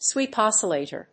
sweep+oscillator.mp3